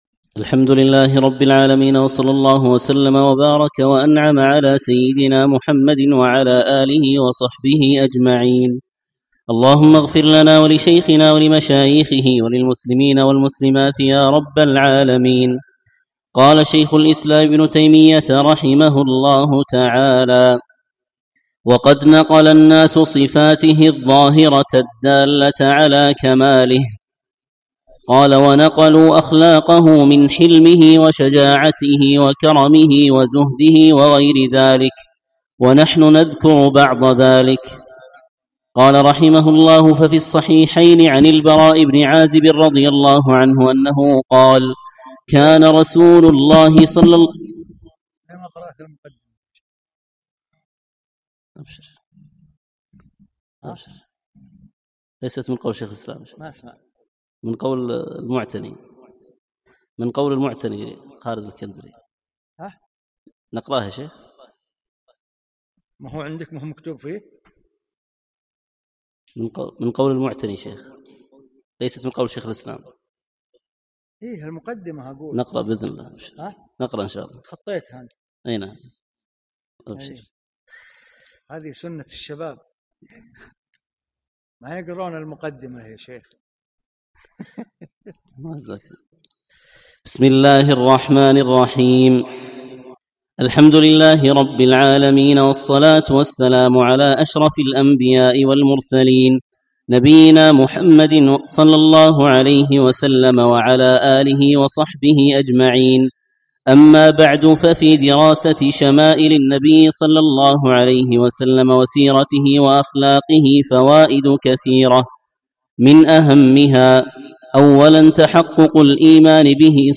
يوم الأثنين 5 شعبان 1438 الموافق 1 5 2017 في مسجد عائشة المحري المسائل